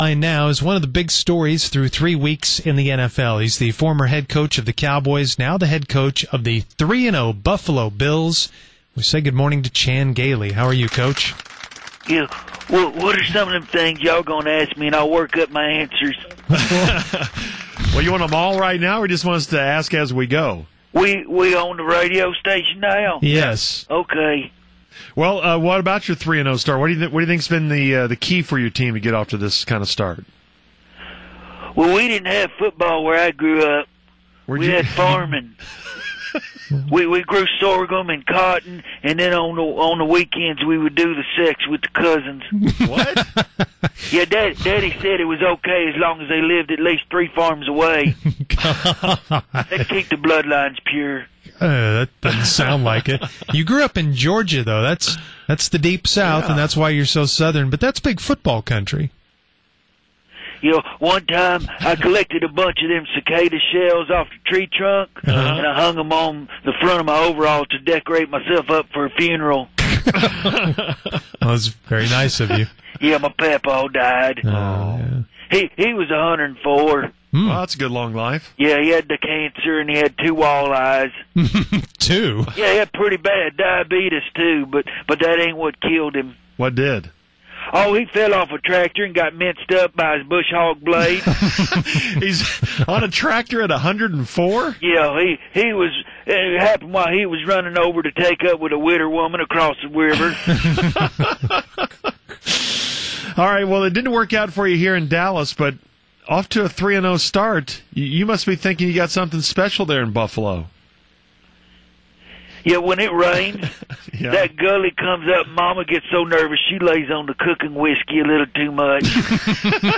fake-chan-gailey.mp3